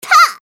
archer_f_voc_skill_spinningfire_c.mp3